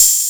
TM-88 Hat Open #08.wav